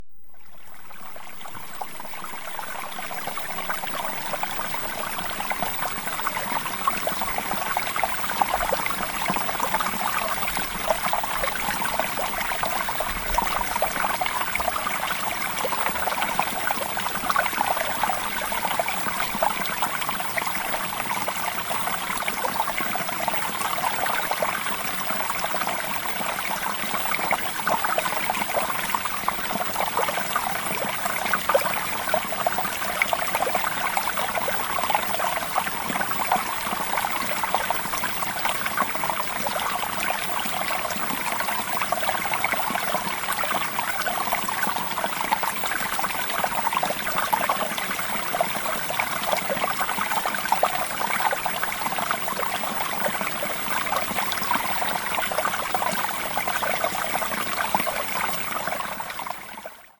OVERCOME-PROCRASTINATION-Water-Sample.mp3